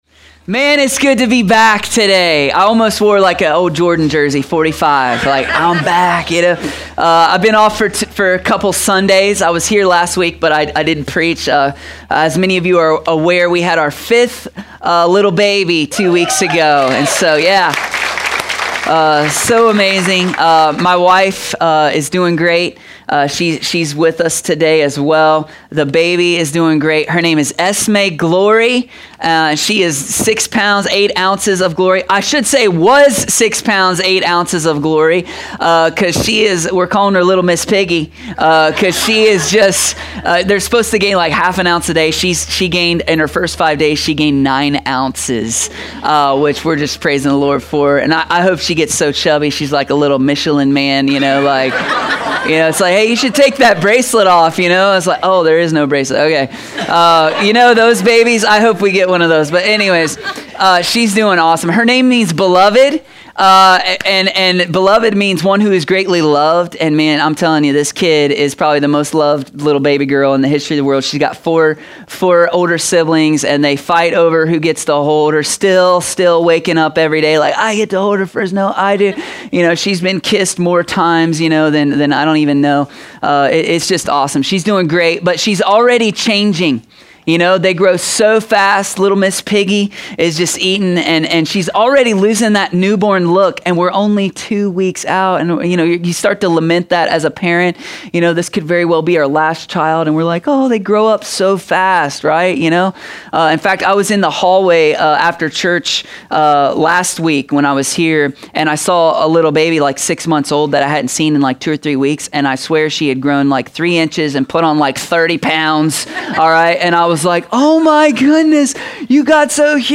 A sermon from the series “Growth Spurt.”…